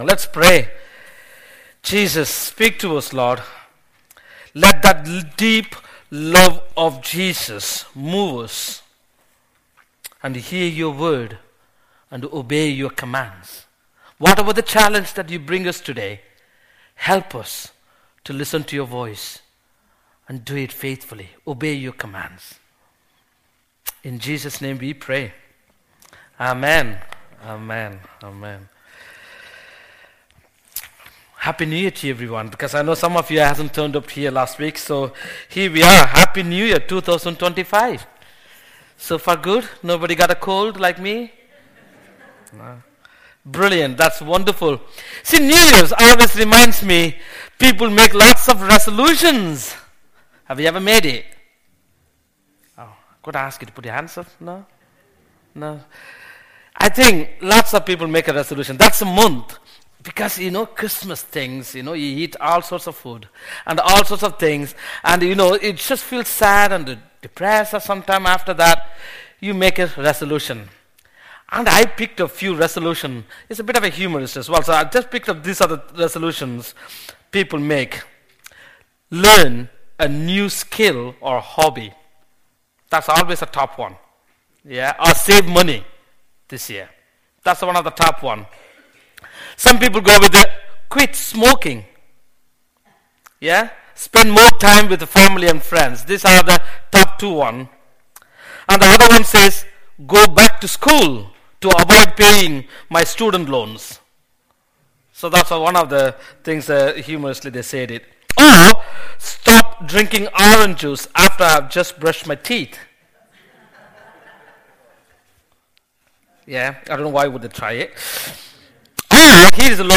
An audio file of the sermon is also available.